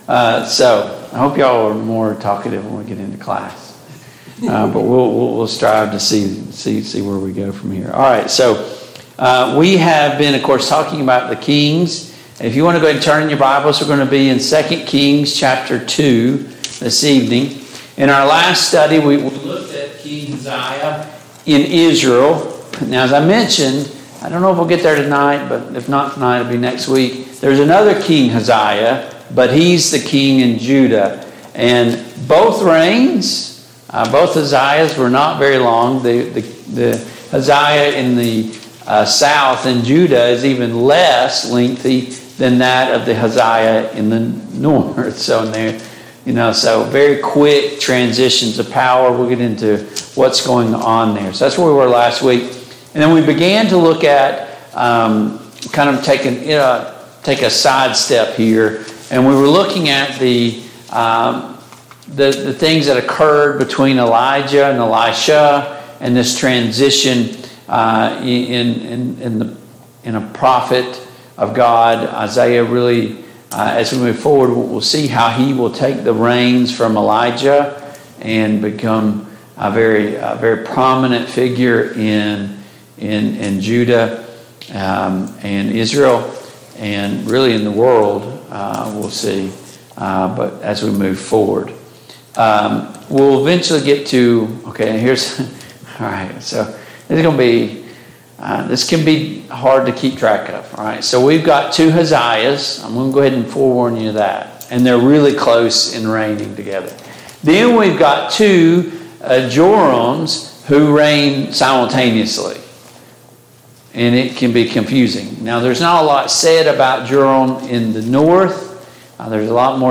The Kings of Israel Passage: 2 Kings 2 Service Type: Mid-Week Bible Study Download Files Notes Topics